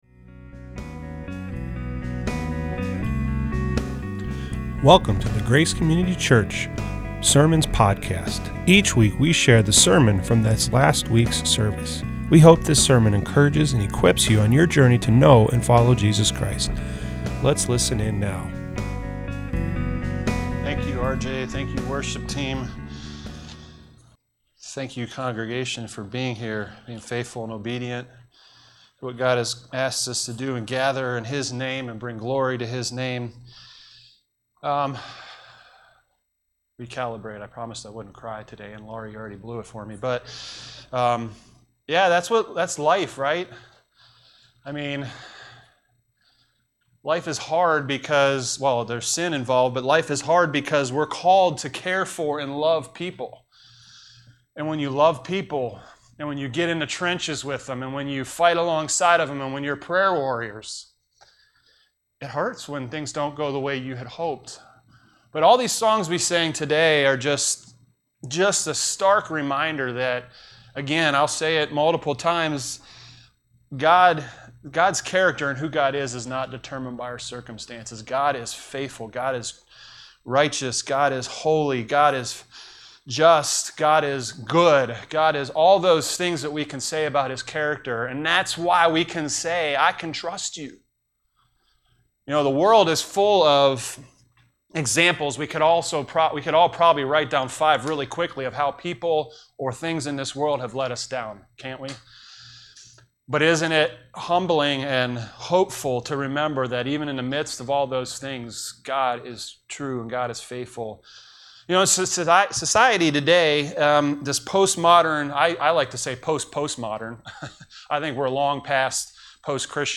This week we continued our new series titled ‘God’s big story, and how YOU fit!’ The title of this message is “The Kingdoms Fall". Primary scripture passages are 2 Kings 21+23.